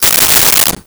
Silverware Movement 02
Silverware Movement 02.wav